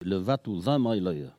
Localisation Saint-Jean-de-Monts
Enquête Arexcpo en Vendée
Catégorie Locution